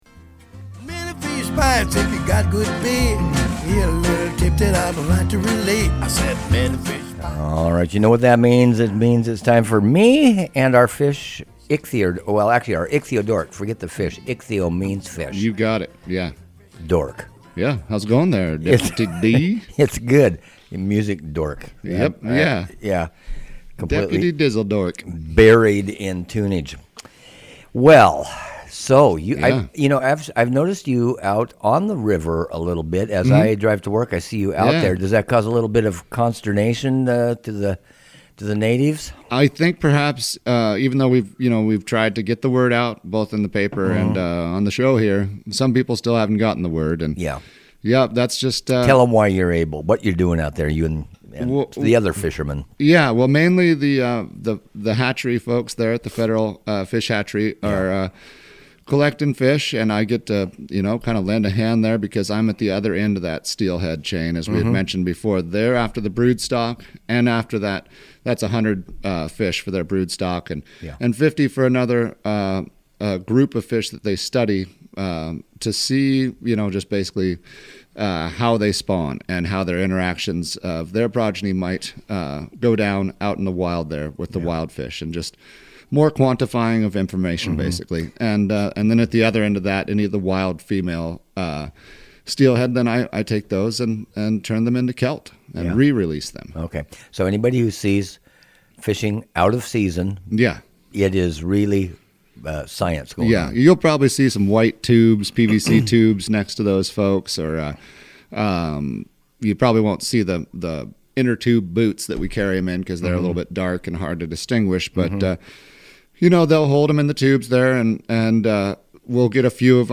KTRT 97.5 Methow Fishing Report